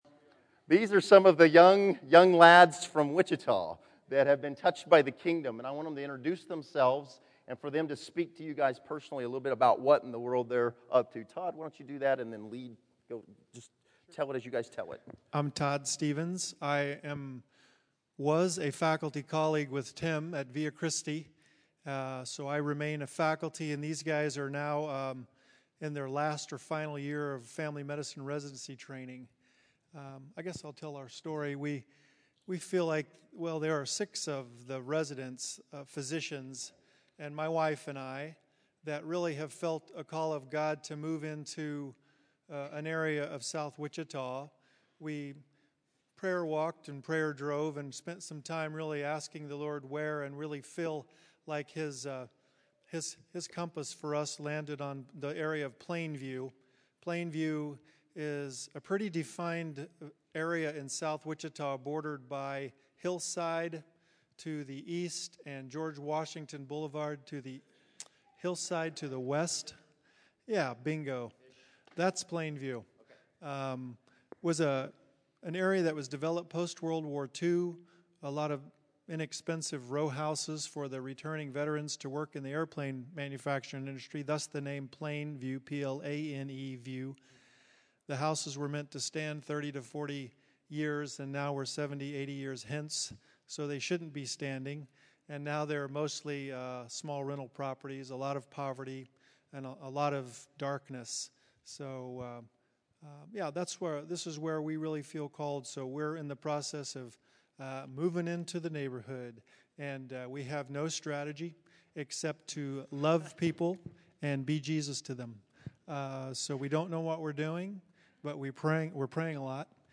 A group of doctors share their mission in the Plainview area of Wichita.